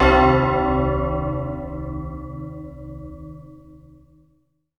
WAVER BELL.wav